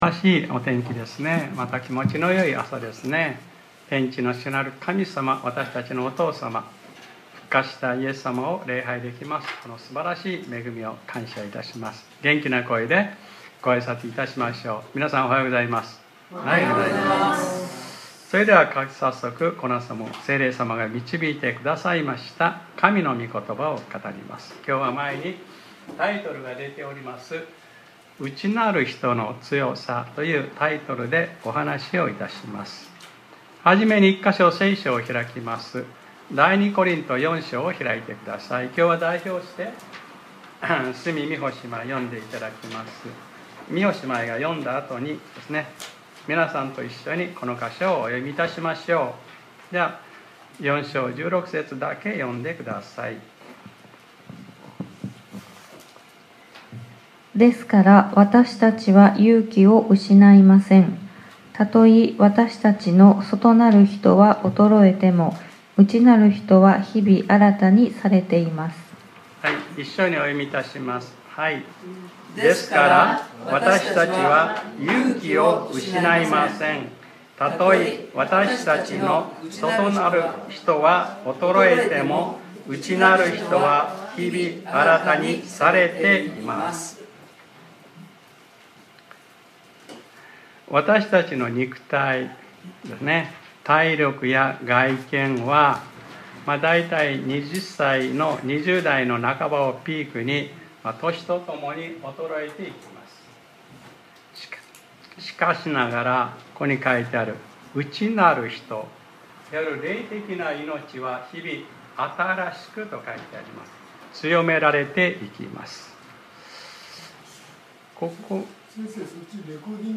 2025年10月12日（日）礼拝説教『 内なる人の強さ 』